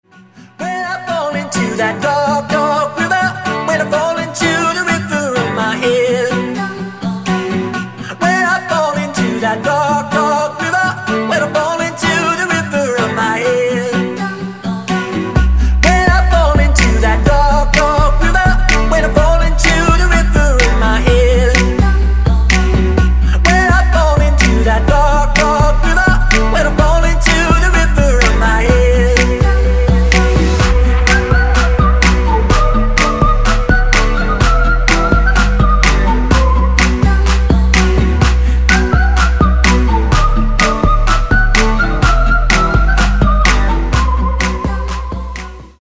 гитара
мужской вокал
club
house
vocal